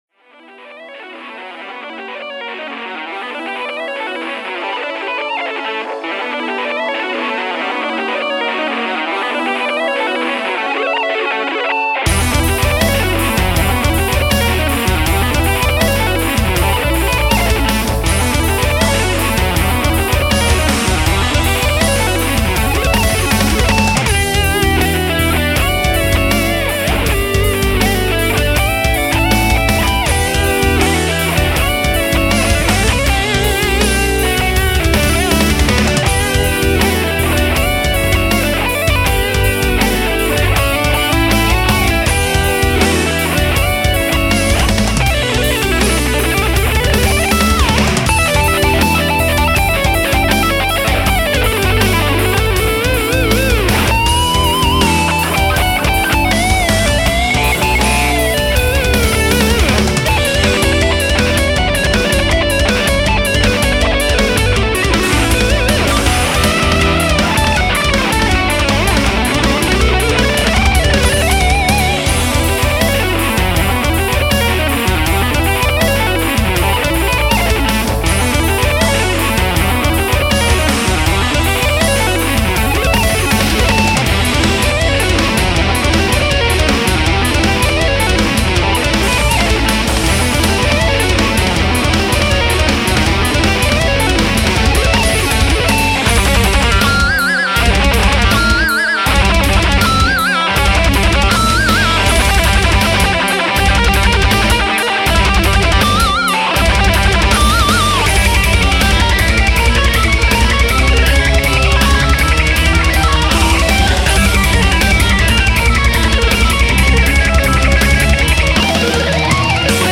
Heavy metal
Rock & Roll
Prog rock